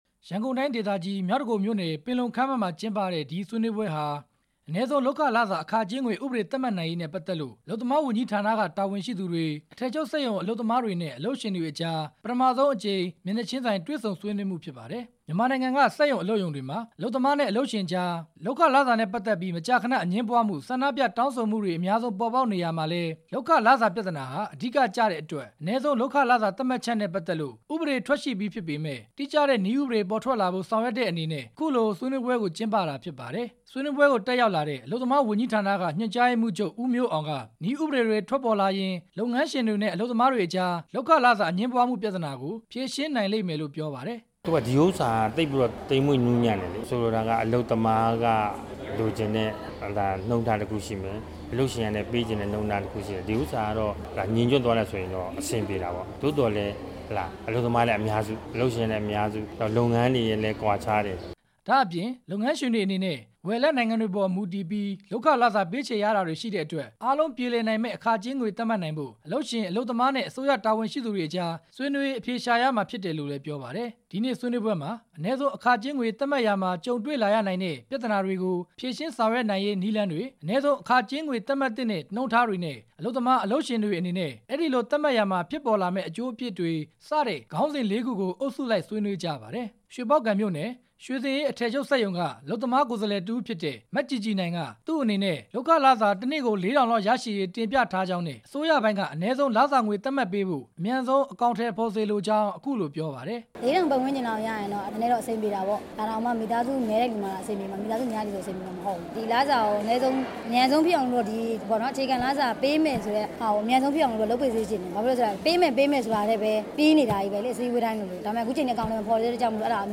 ဆွေနွေးပွဲအကြောင်း တင်ပြချက်